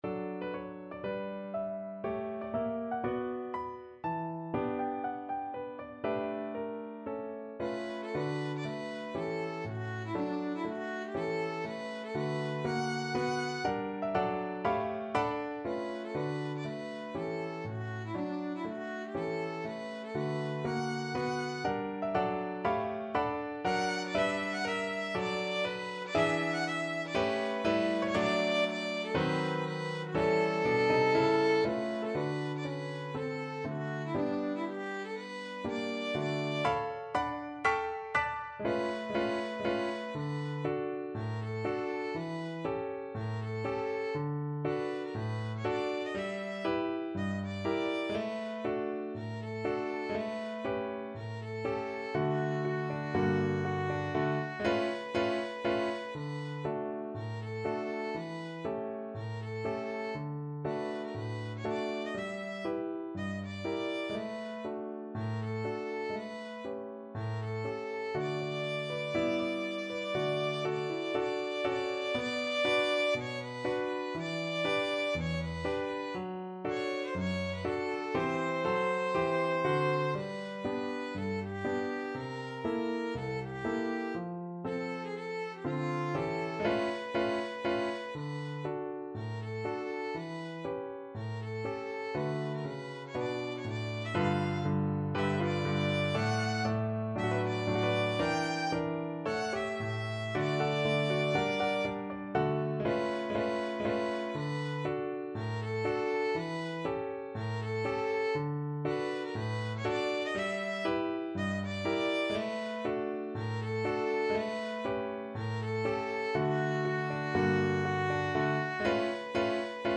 Violin version
~ = 120 Moderato
4/4 (View more 4/4 Music)
D5-G6